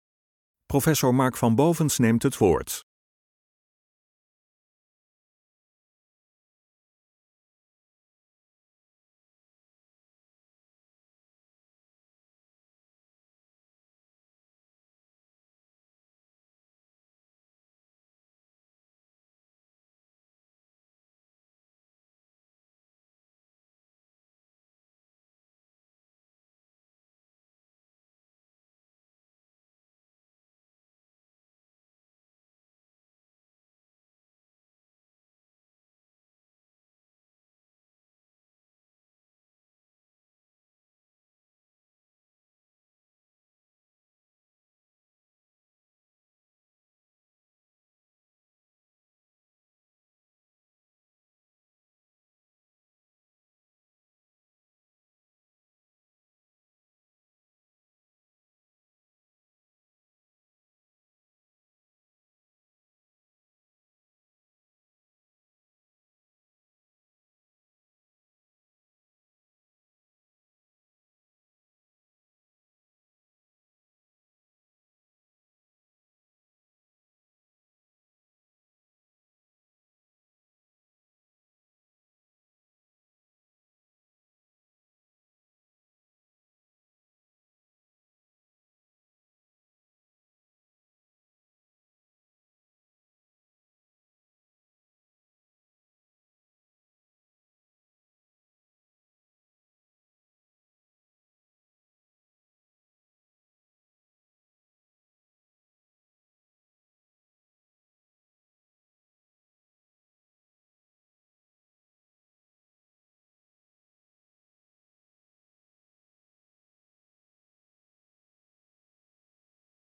Presentatie rapport Grip met toelichting
De video is verrijkt met dia's uit de presentatie om een bondige weergave te geven van de kern van het rapport.